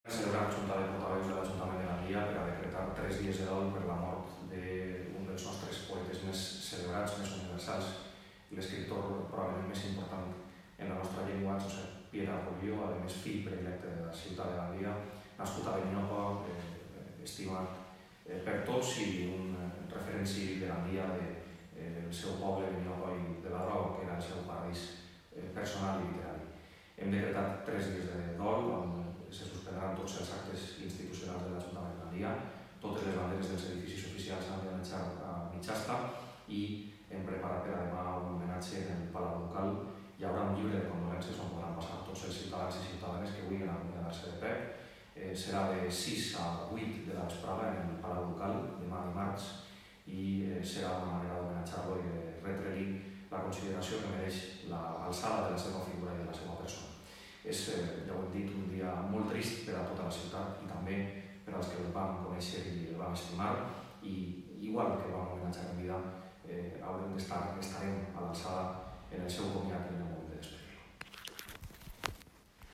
Puedes escuchar las declaraciones del alcalde